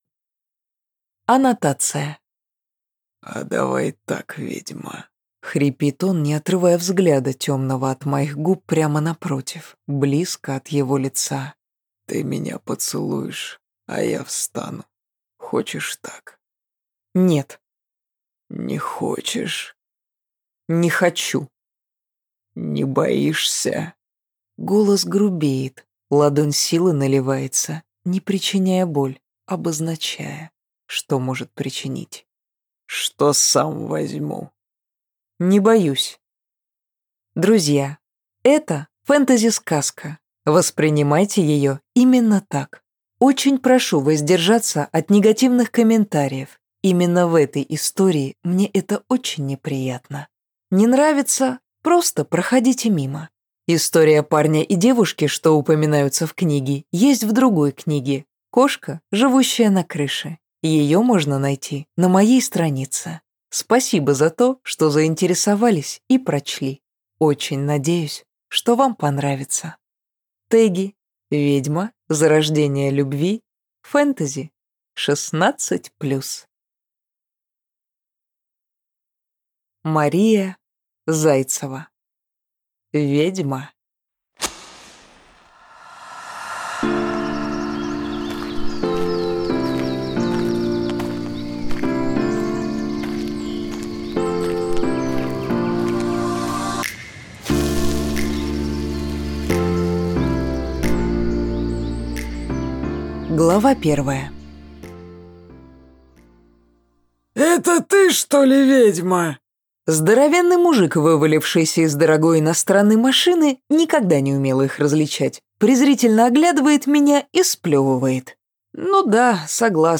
Аудиокнига Ведьма | Библиотека аудиокниг